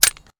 weapon_foley_drop_13.wav